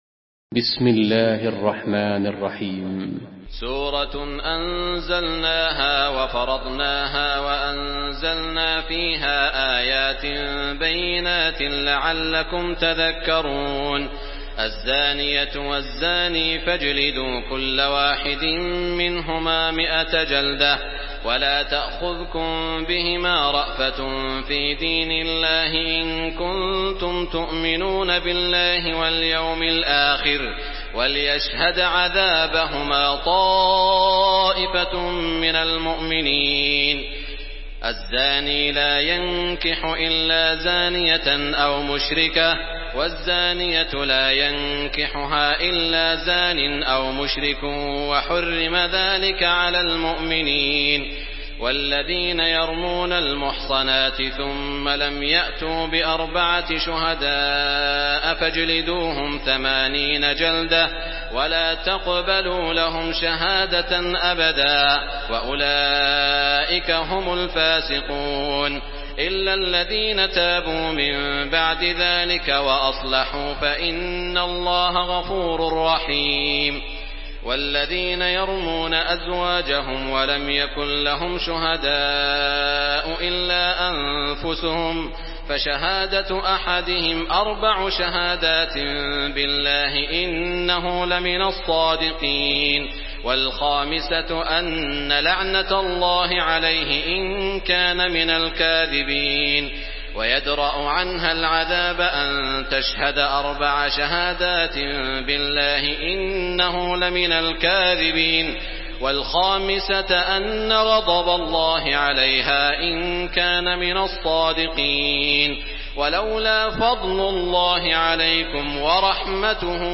Surah النور MP3 by سعود الشريم in حفص عن عاصم narration.
مرتل